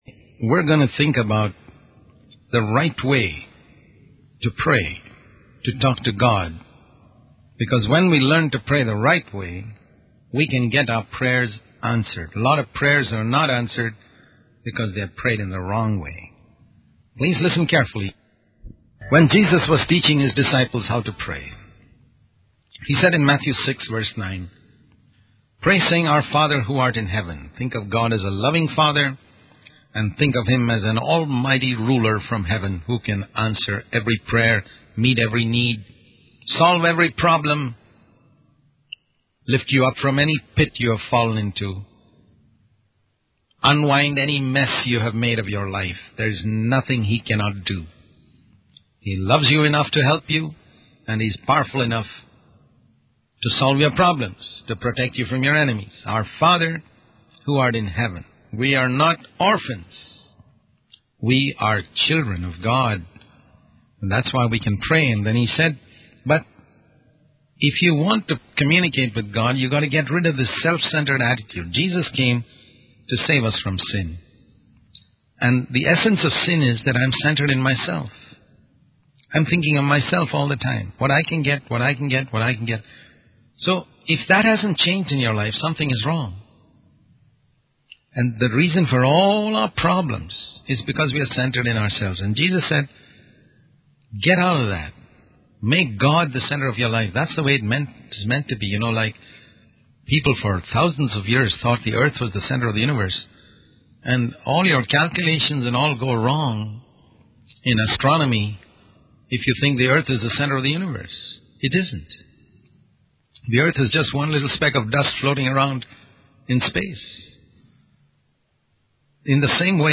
In this sermon, the preacher emphasizes the importance of immediately obeying God's commands. He contrasts the delay and reluctance of humans to obey with the promptness and joyfulness of angels.